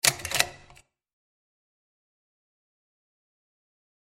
Шорох перелистывания слайдов в проекторе